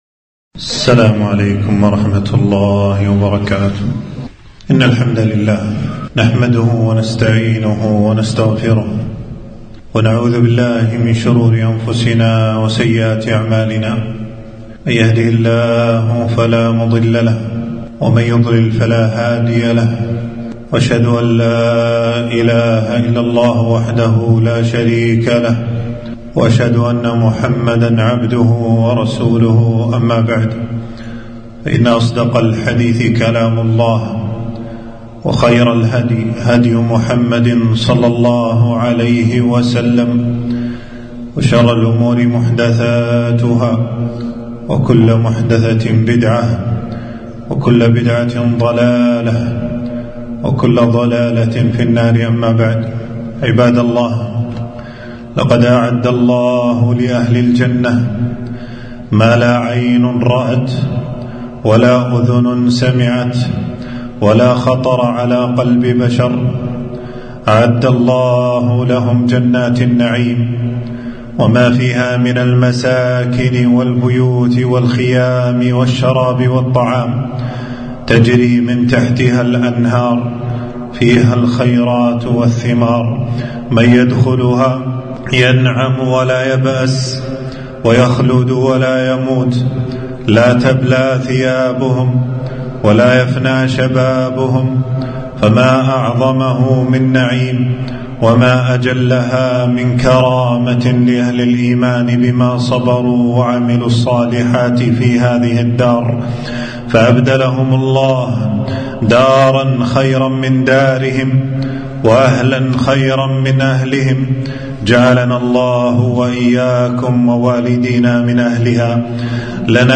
خطبة - مساكن أهل الجنة وبيوتهم وخيامهم